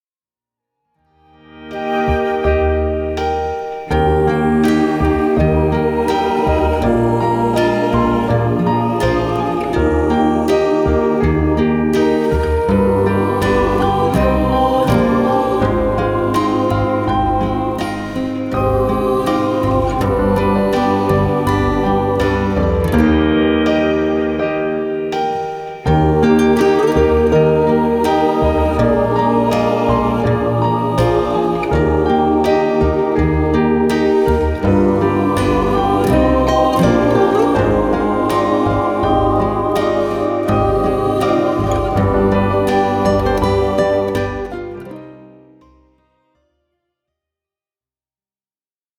Play-Back